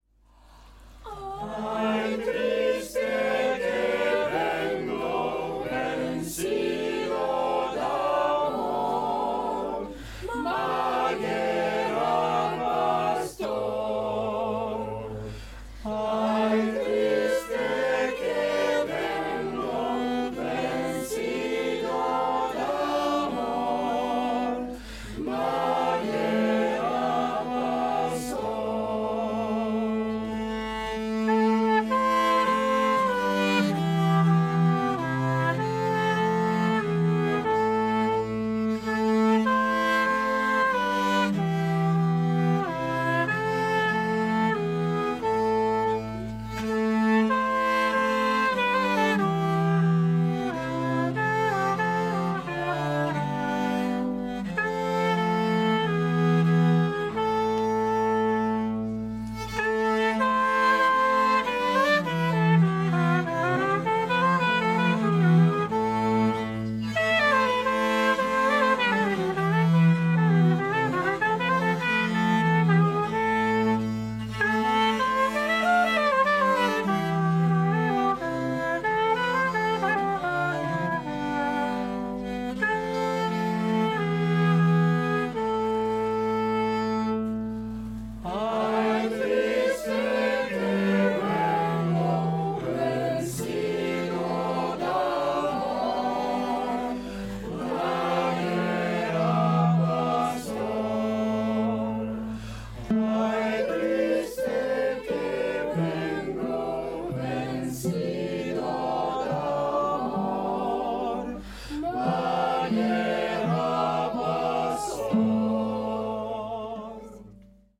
Format: MP3 Ay triste que vengo 3 Mio Musique et danse baroque